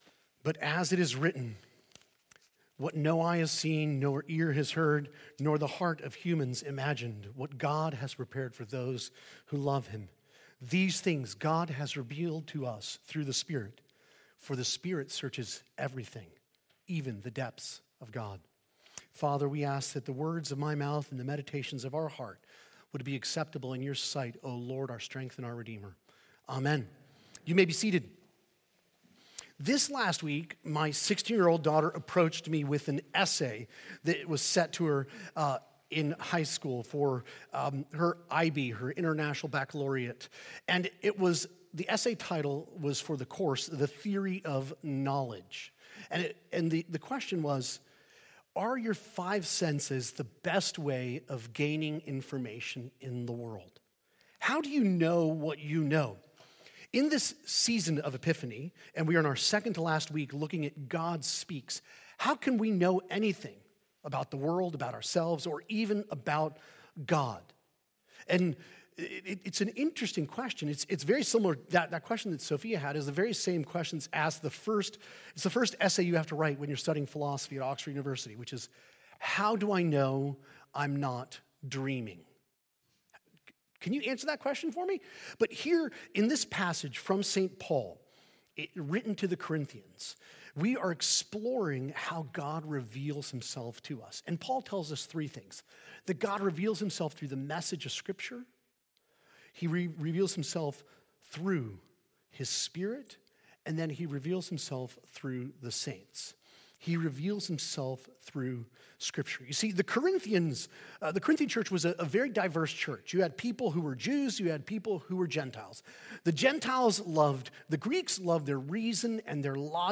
Sermon preached